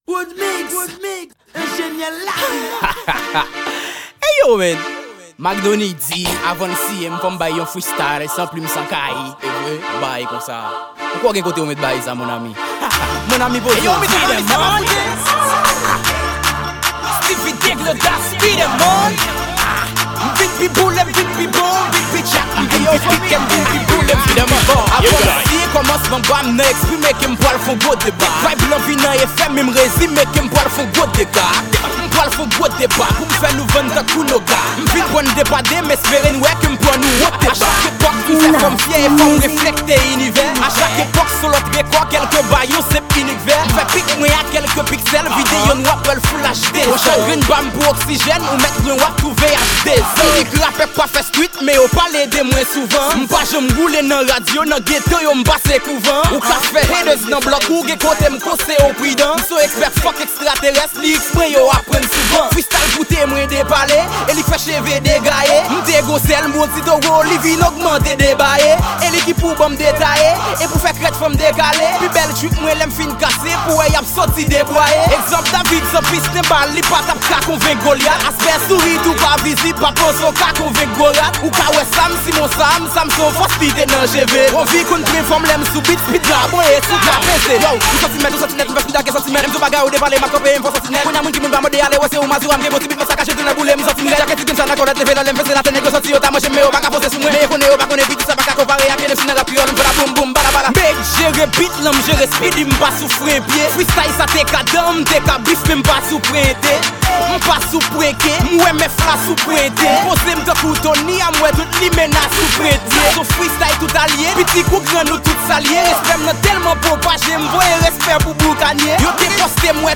Genre: Freestyle.